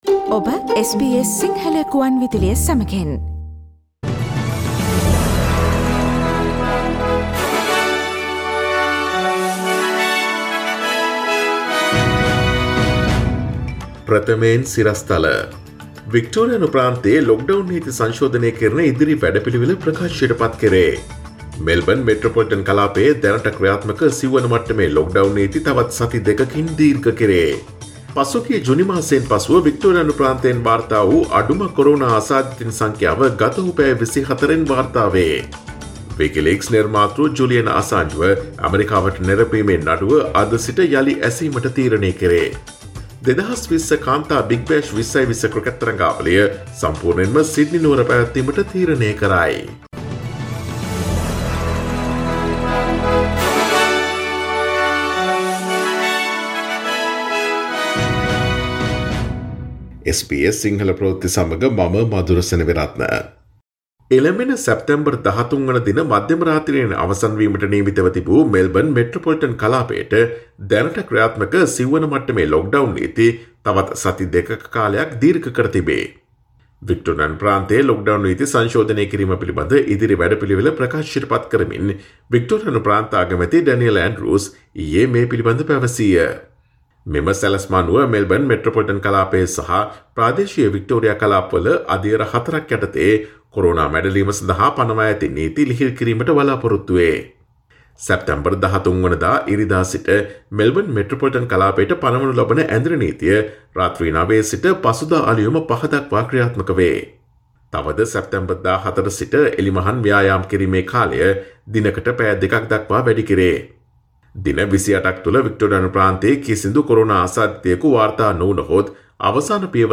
Daily News bulletin of SBS Sinhala Service: Monday 07 September 2020